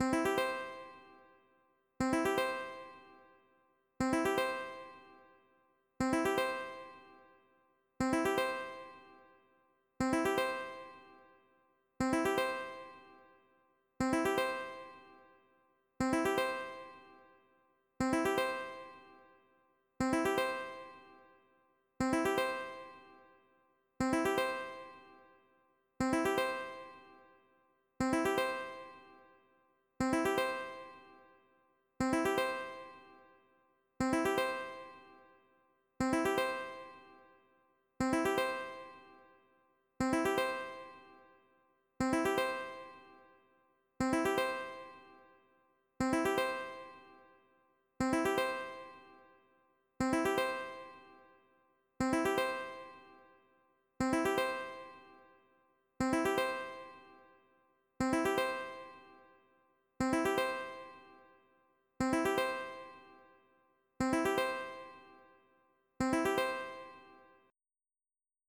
Experimental Calm 01:08